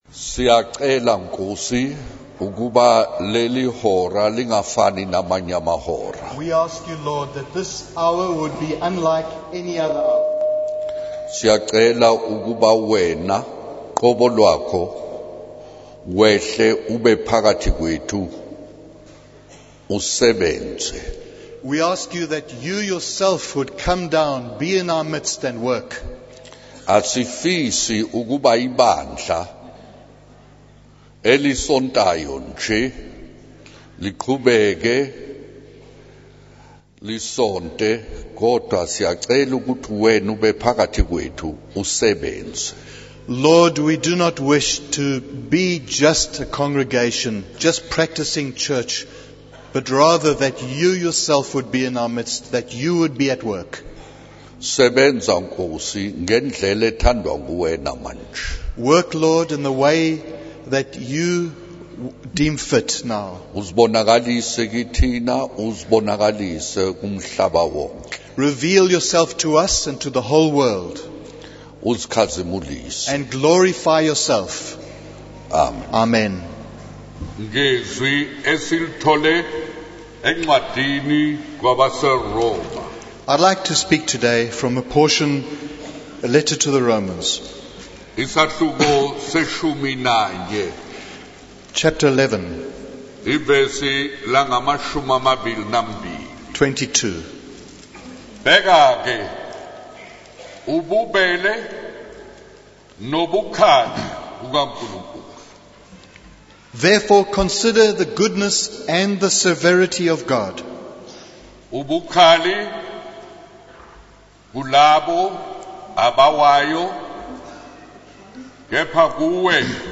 In this sermon, the preacher discusses the concept of the kindness and severity of God. He shares a personal story about an American preacher who asked whether people emphasize the love of God or His wrath. The preacher explains that both aspects exist and cites a biblical reference in Matthew 24 about an evil servant.